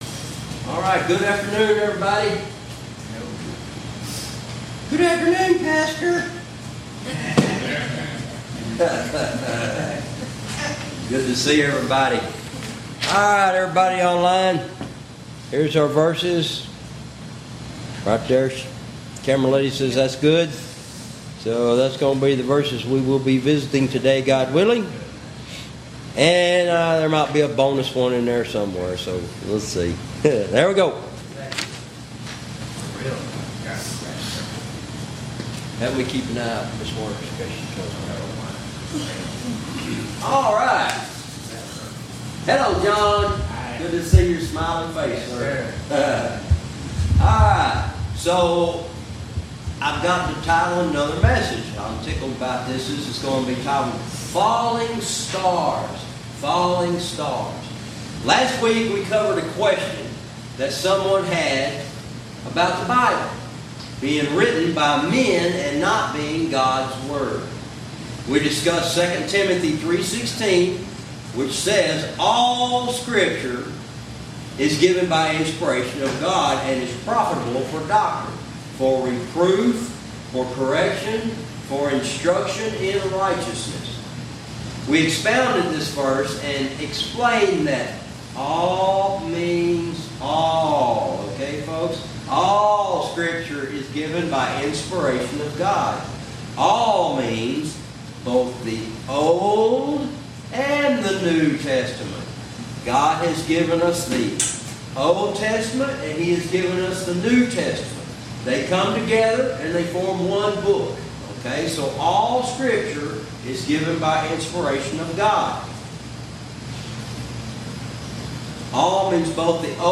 Verse by verse teaching - Jude Lesson 55 -"Falling Stars"